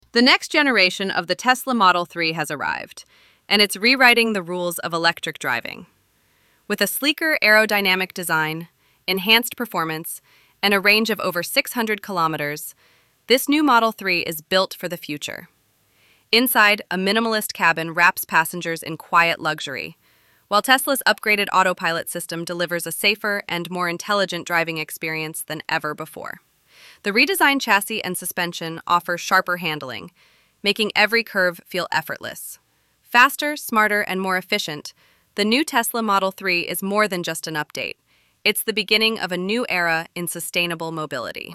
AI narration • Black background • Electric blue text • Glowing blue shadow